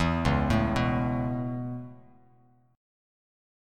C#m7#5 Chord